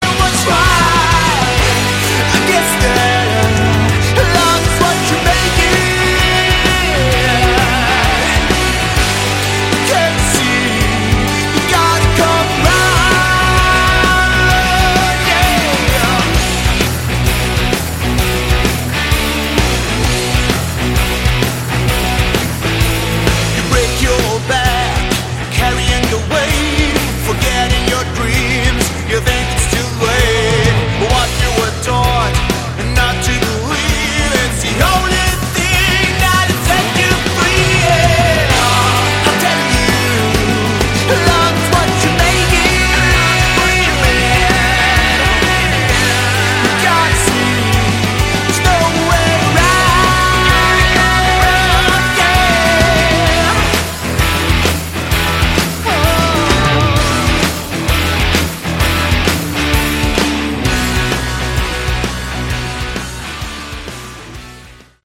Category: Hard Rock
vocals, guitar
bass, backing vocals
drums, percussion